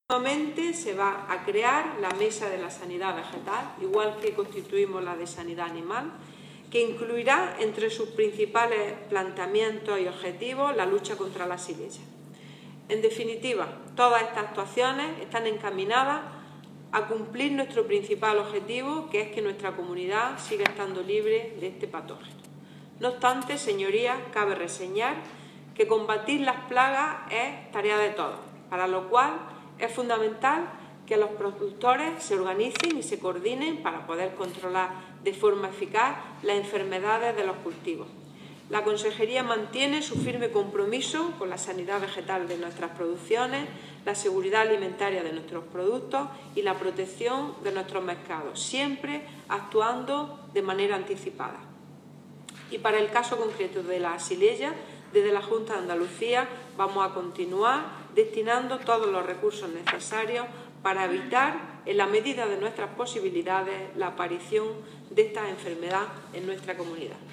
La consejera, en comisión parlamentaria.
Declaraciones consejera sobre Xylella